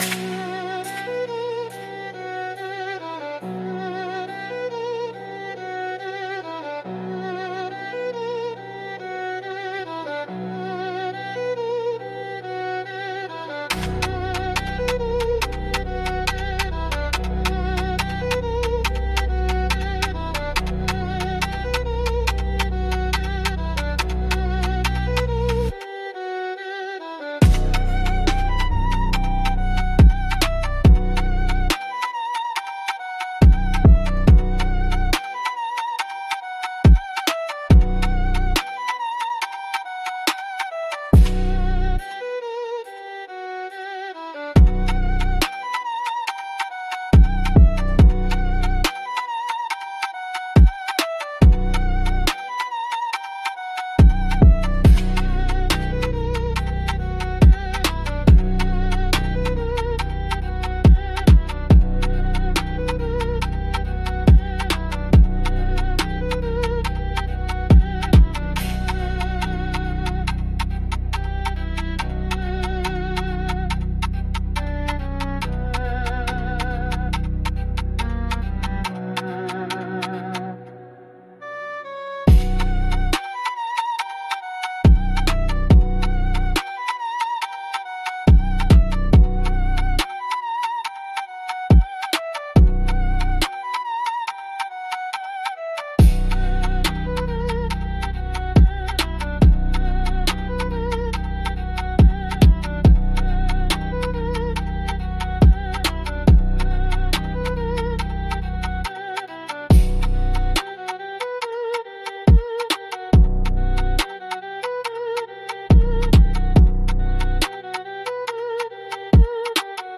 B Major – 70 BPM
Drill
Trap